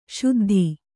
♪ śuddhi